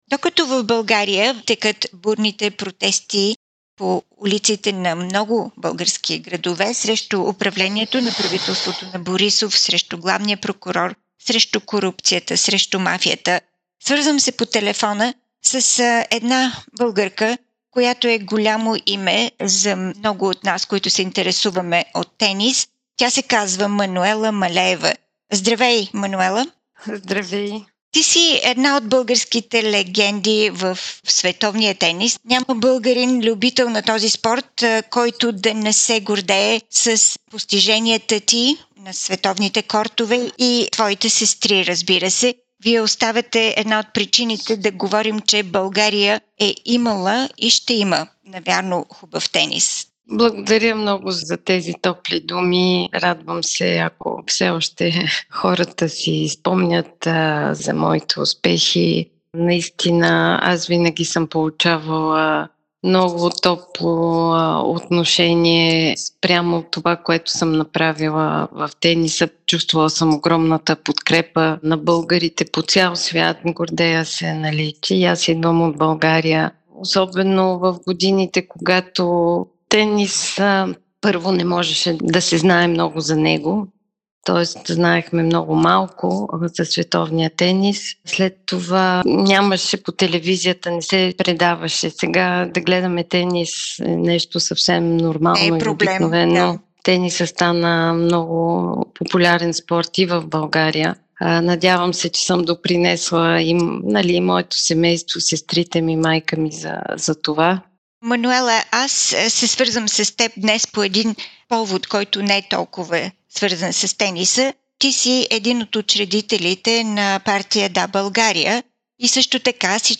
Мануела Малеева – ексклузивно интервю за Българската програма на SBS